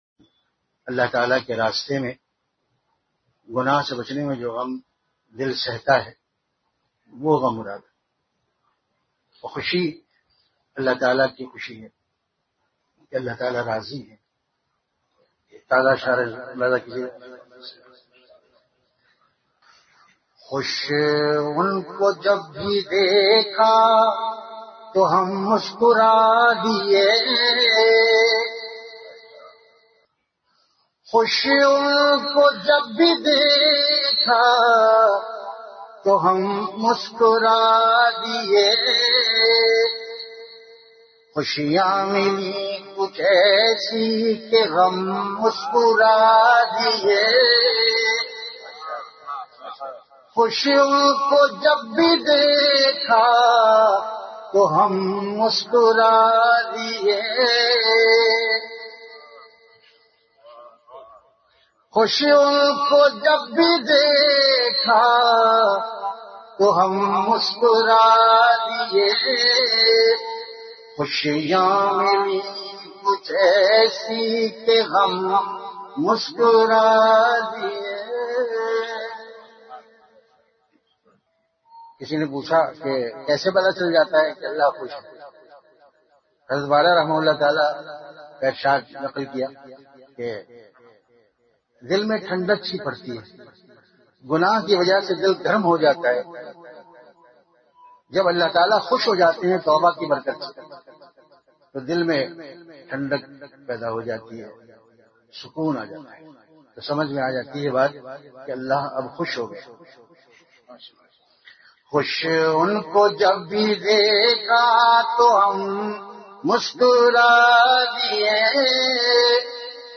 Delivered at Home.
Event / Time After Isha Prayer